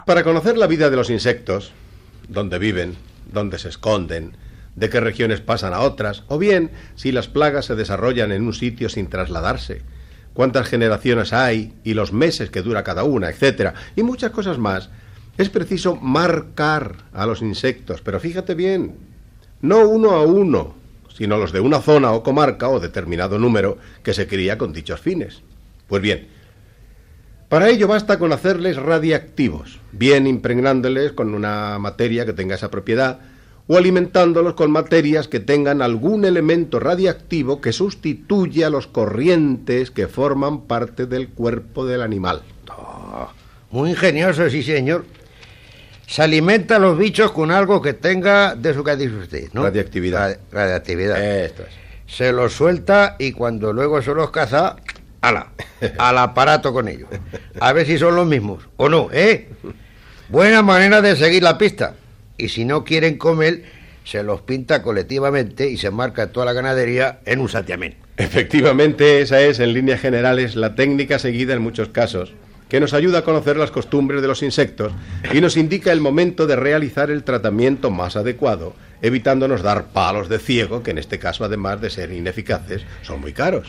Diàleg sobre el marcatge d'insectes per al seu estudi
Divulgació